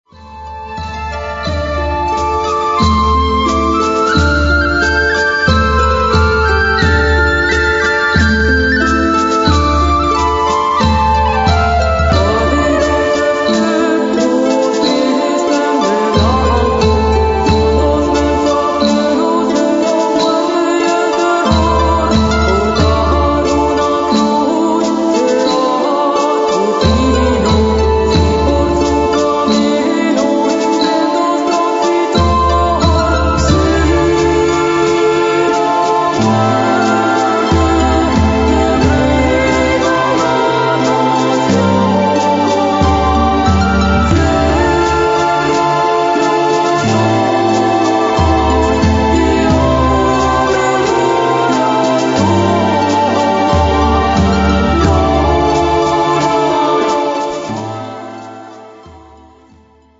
Vocals, Percussion
Violin, Backing Vocals
Keyboards, Drums, Backing Vocals